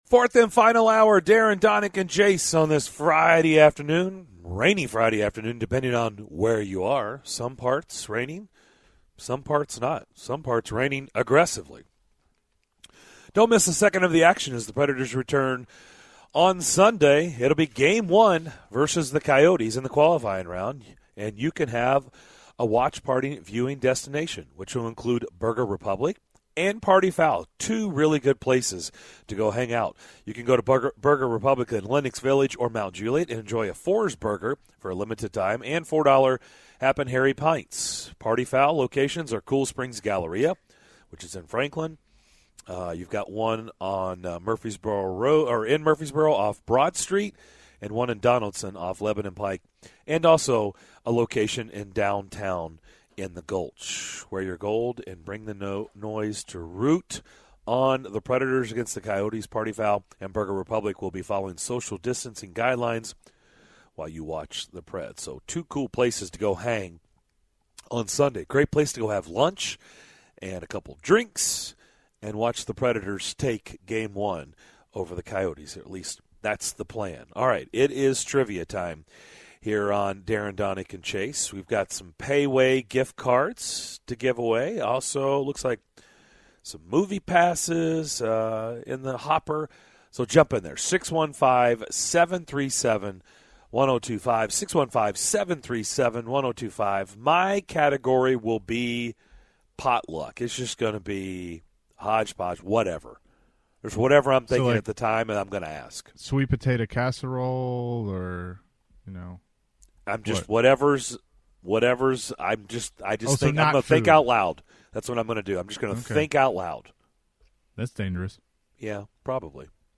In the final hour of Friday's show: the show's listeners call in to try to win prizes in this week's Friday trivia segments!